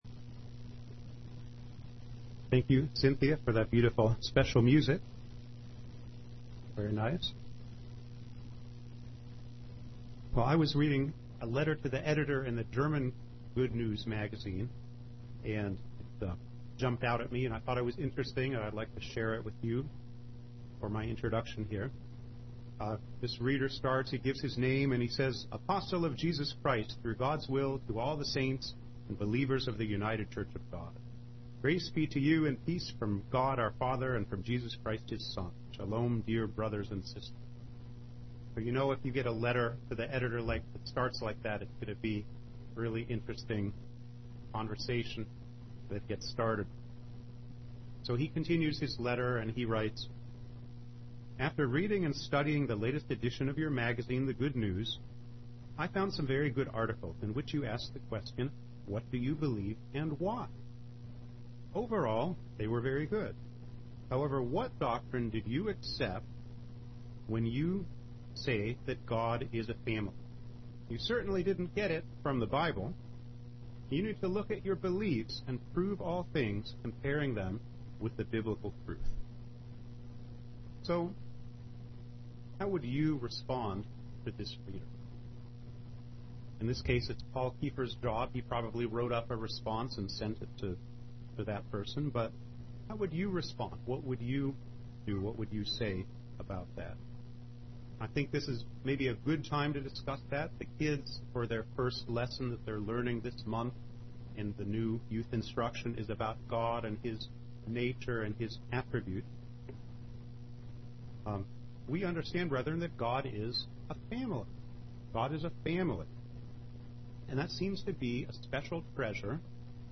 UCG Sermon Notes Notes: How would we respond if someone questioned whether God is a family?